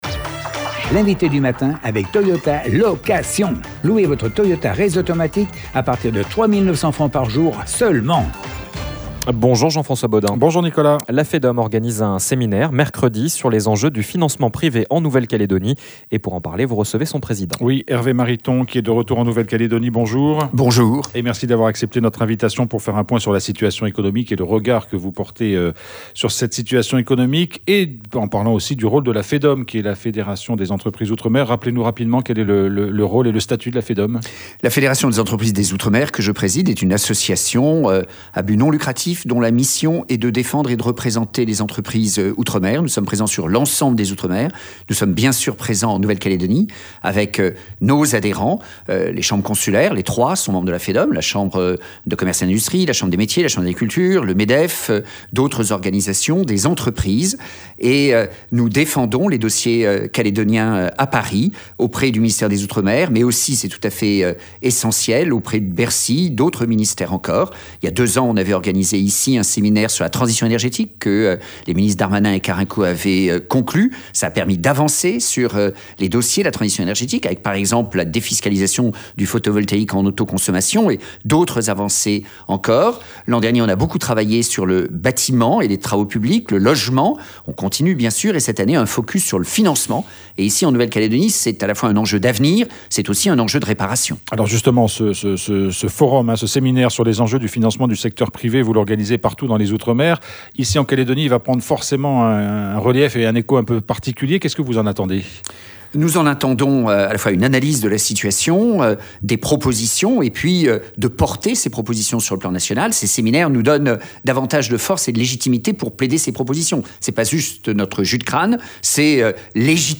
Hervé Mariton, ancien Ministre des Outre-Mer, ancien député de la Drome, et aujourd’hui président de la FEDOM était notre invité du matin ce lundi. Quel regard porte-t-il sur la situation actuelle en Calédonie, et notamment sur les défis économiques ?